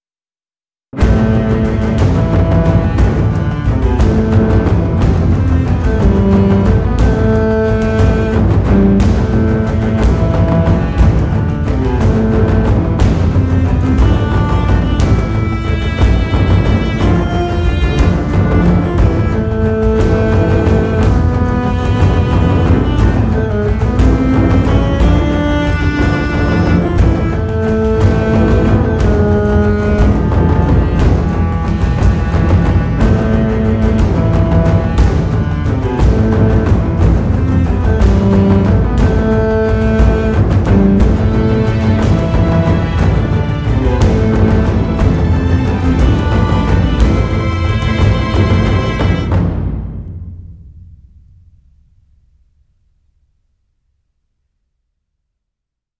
LSS大提琴独奏 Aria London Solo Cello KONTAKT-音频fun
大提琴与LSS小提琴在同一个伦敦音乐厅采样，一位出色的音乐家在为该图书馆录音时应用了完美的雅致音乐性和情感。结果是一个主要是人类的，可呼吸的仪器。
这是演奏者以尖锐的渐强和快速加重音调的弓声结束音符的声音。